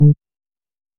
Ping